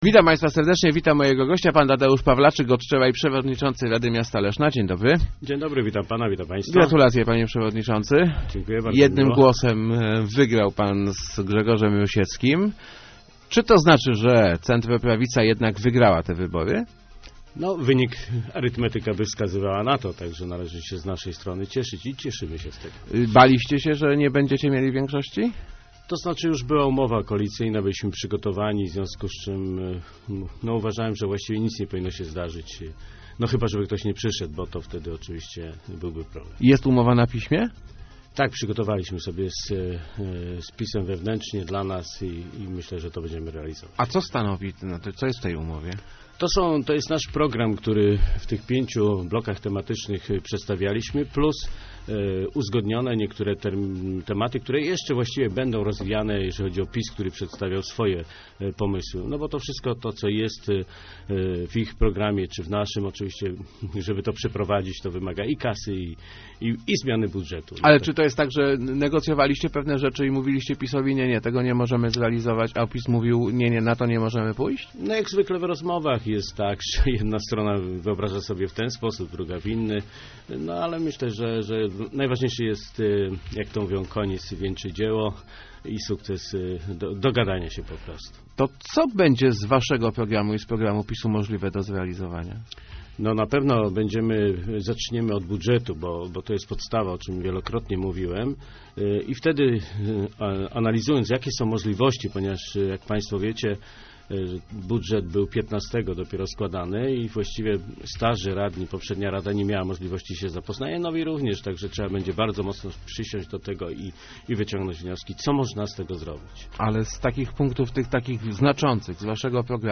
thumb_pawlaczyk80.jpgKoalicja PO i PiS w Radzie Miasta Leszna nie będzie paraliżować działań samorządu - mówił w Rozmowach Elki Tadeusz Pawlaczyk, nowy przewodniczący rady. Jest on przekonany, że uda się dojść do porozumienia z prezydentem Tomaszem Malepszym dla dobra miasta. Pawlaczyk zapowiada, że centroprawicowa koalicja będzie kontrolować strategiczne komisje rady miejskiej.